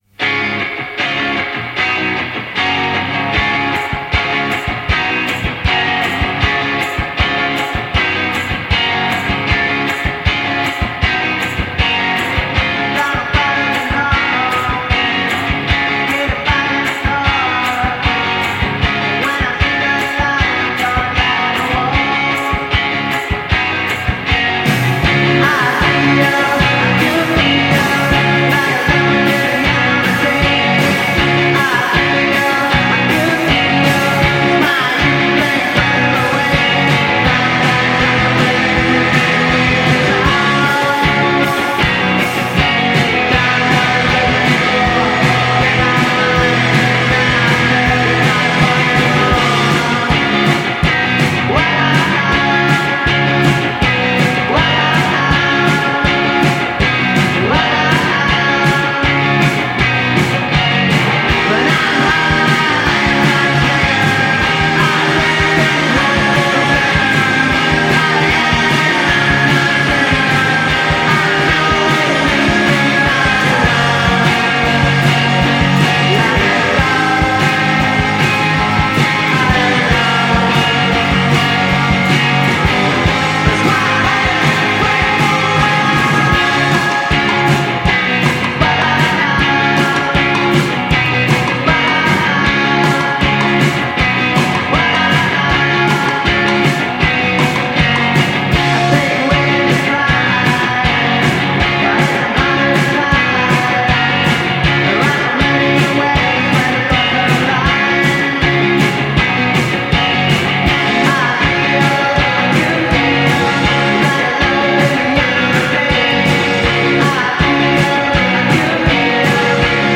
lo-fi garage duo